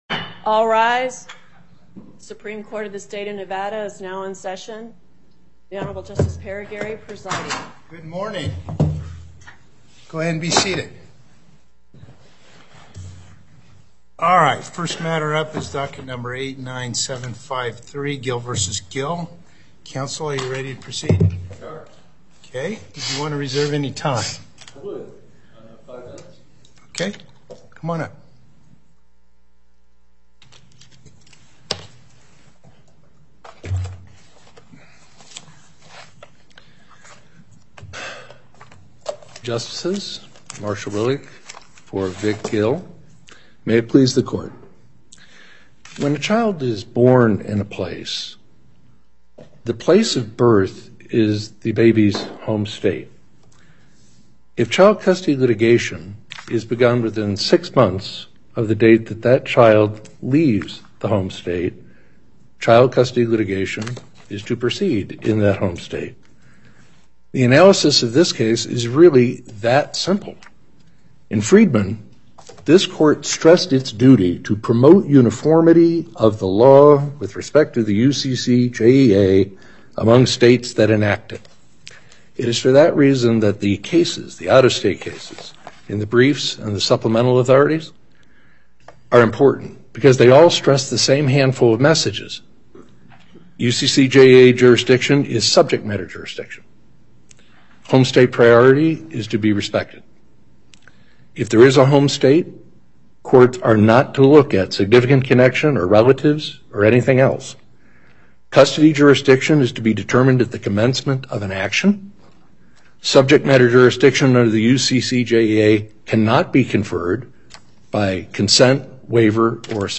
Before Panel A25 Justice Parraguirre presiding Appearances